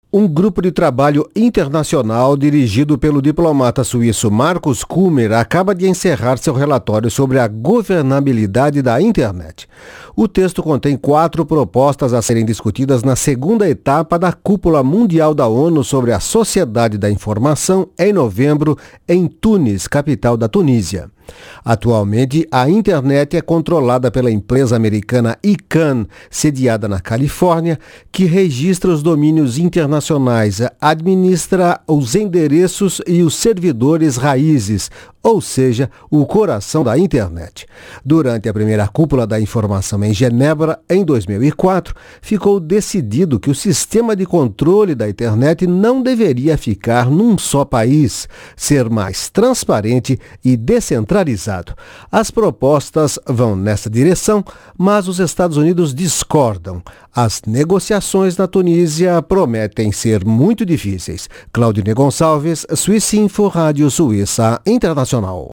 Há quatro meses da cúpula da informação na Tunísia, o diplomata suíço revela a swissinfo os principais ítens em que houve avanços, embora tímidos.